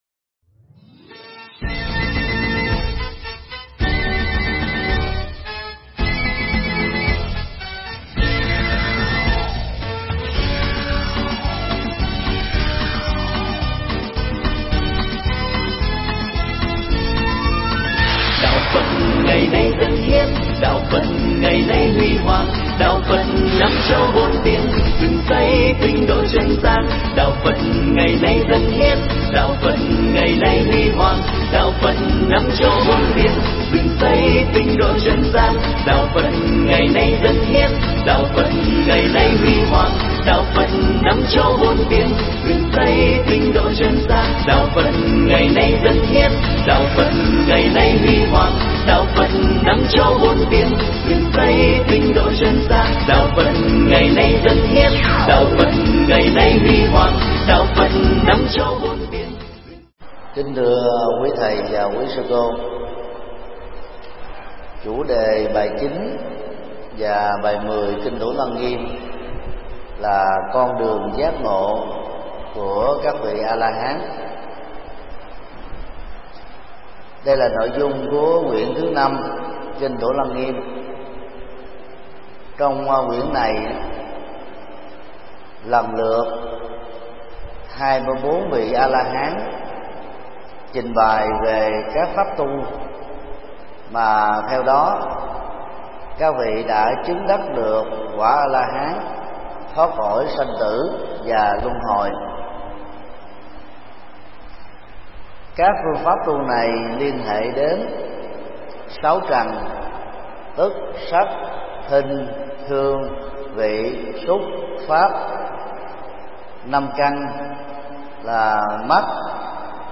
Mp3 Pháp thoại Kinh Thủ Lăng Nghiêm 9
Giảng tại Học viện Phật giáo Việt Nam tại TP. HCM